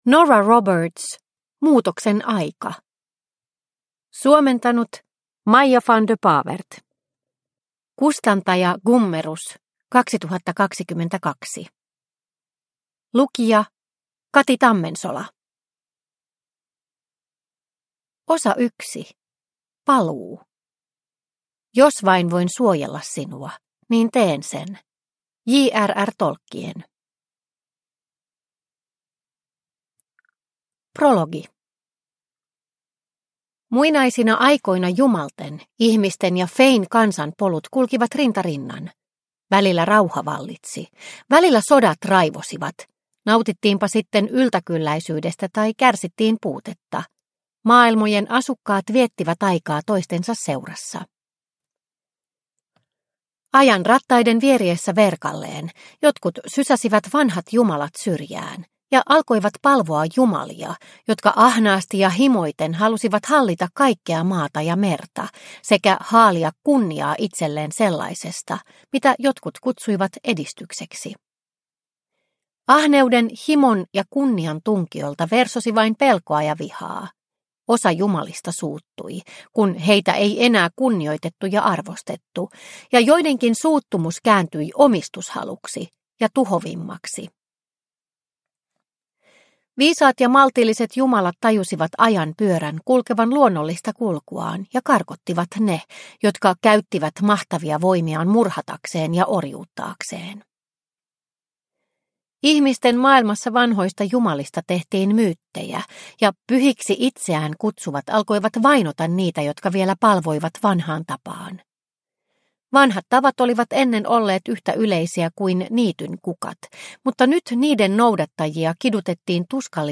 Muutoksen aika – Ljudbok – Laddas ner